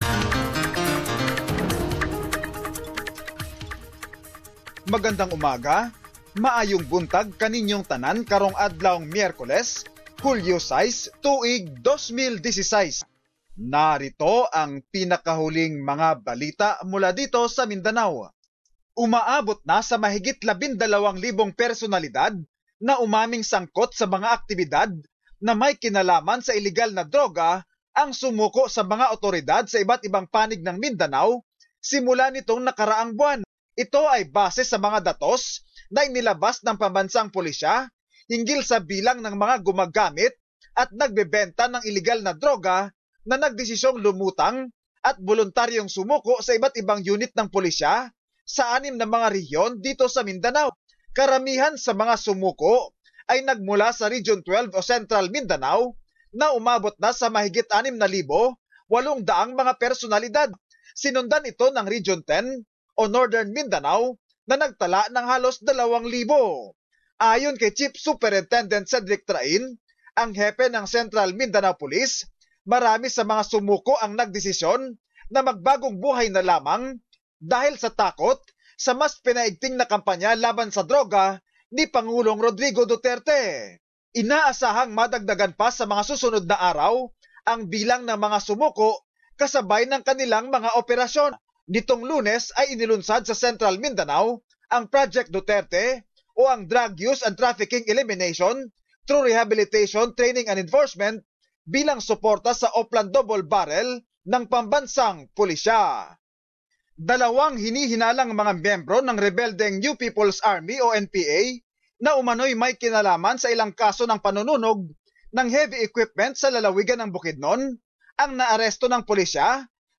Summary of latest news from the region